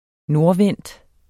nordvendt adjektiv Bøjning -, -e Udtale [ -ˌvεnˀd ] Betydninger som vender mod nord Der var svalt i det nordvendte kammer, og det ville der være hele dagen BerlT1995 Berlingske Tidende (avis), 1995.